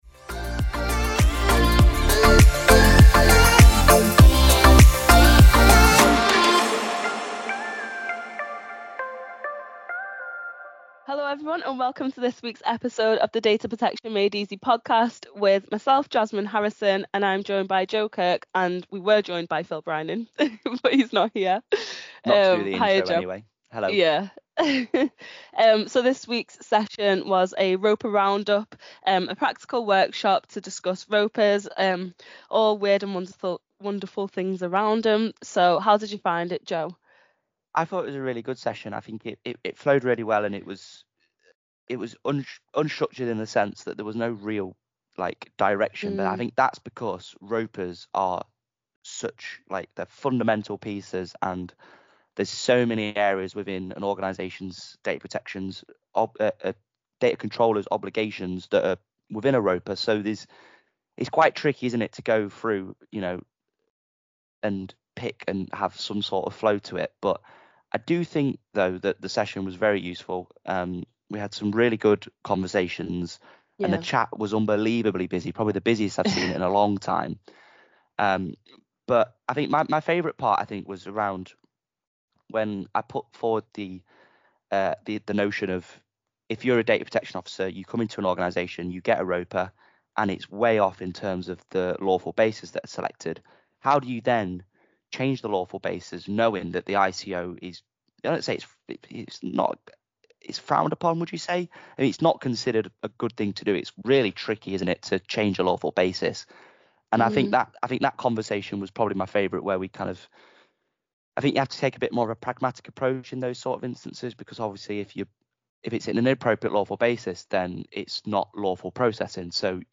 RoPA Roundup - A Practical Workshop for DPOs